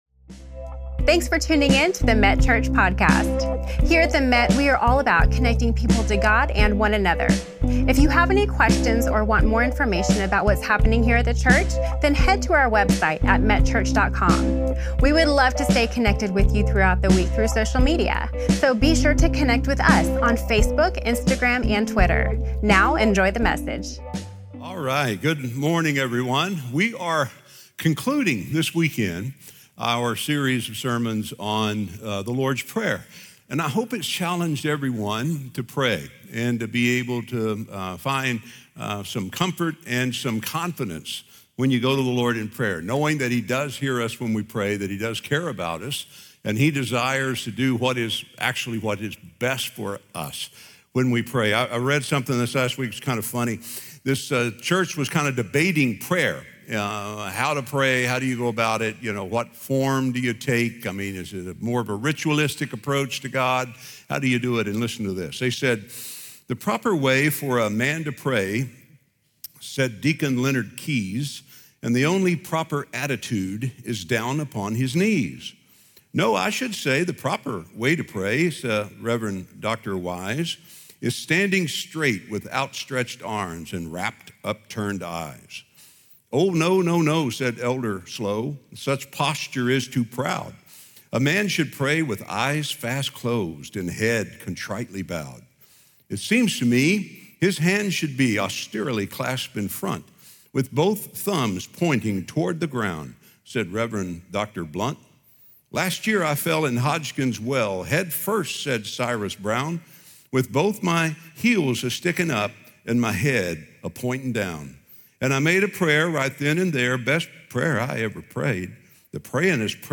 With clarity, depth, and relatable storytelling, he walks us through what it means to recognize God's authority, trust His power, and rest in His eternal presence.